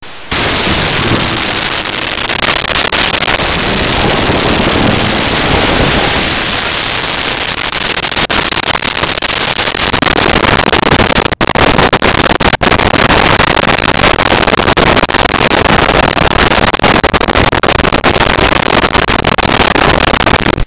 Explosion Large Debris Fire
Explosion Large Debris Fire.wav